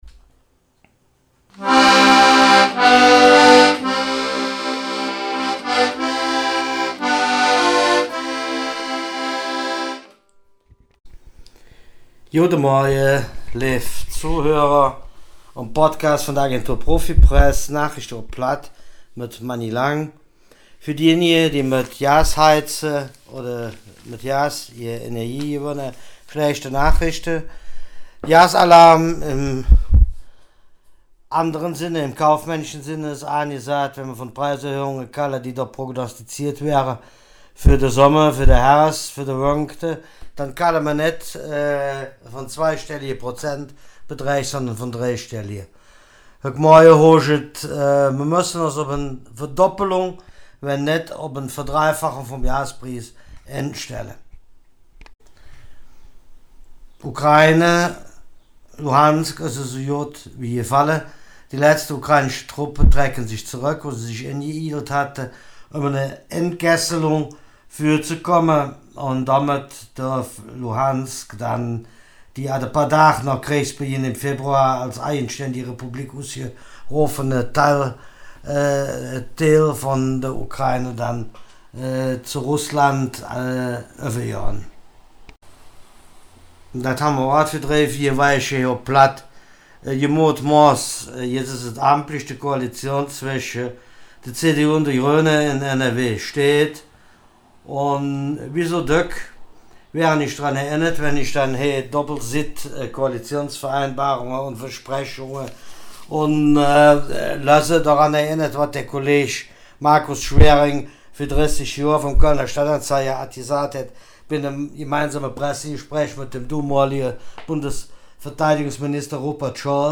Nachrichten vom 24. Juni
nachrichten-vom-24-juni.mp3